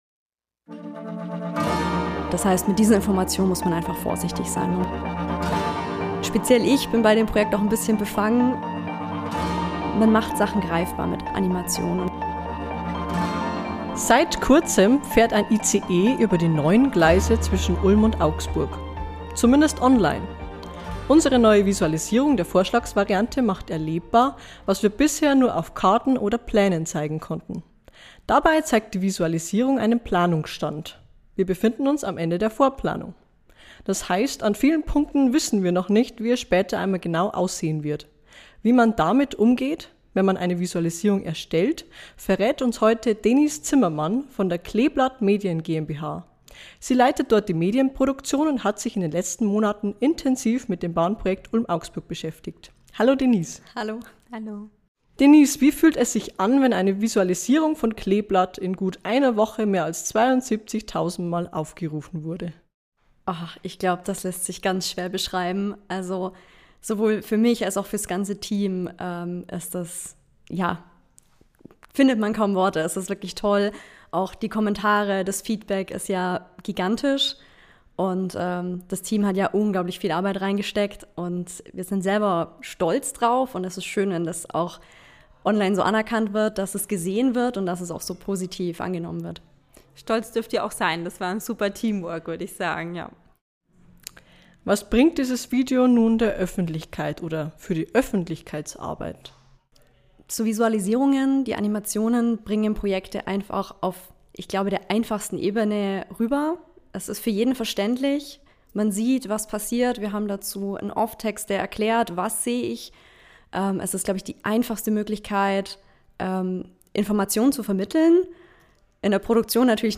Animationen sind mehr als nur schöne Bilder. Sie schaffen Vertrauen, bauen Ängste ab und machen technische Details emotional erlebbar. Ein Gespräch